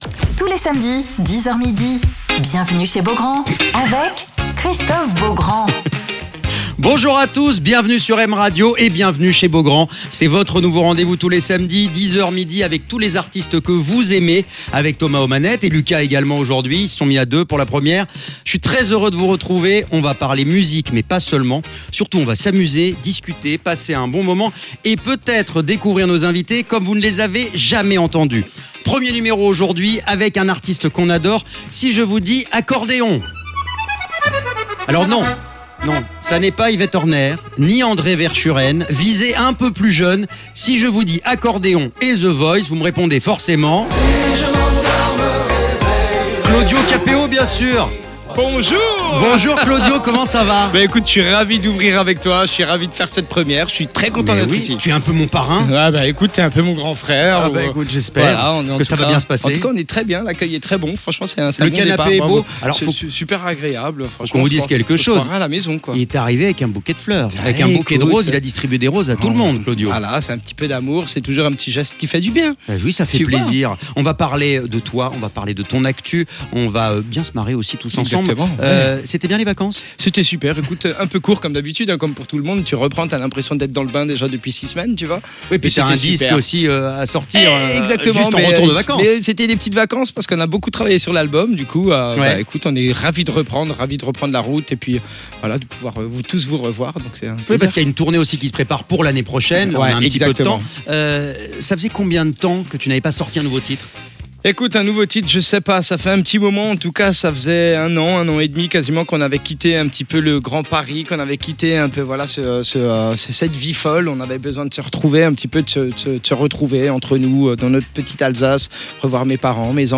Alors qu'il est de retour avec son nouveau titre "Madame" Claudio Capéo est l'invité de Christophe Beaugrand sur M Radio